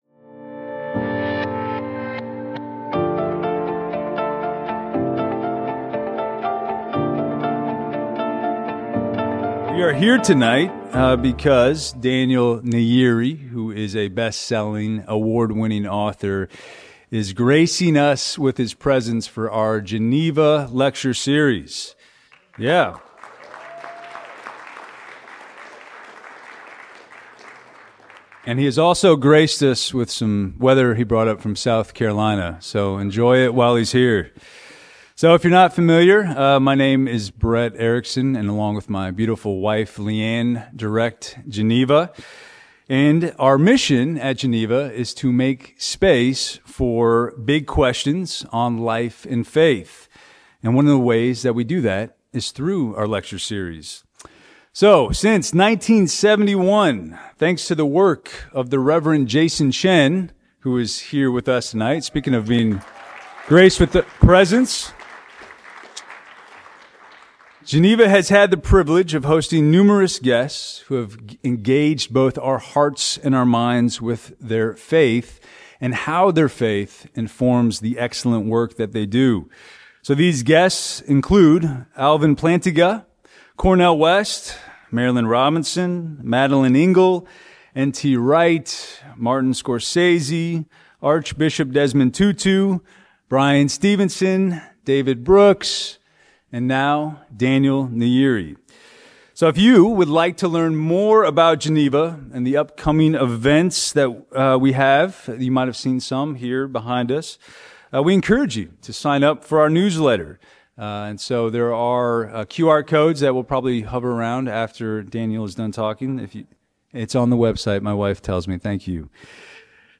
Geneva Lecture - Daniel Nayeri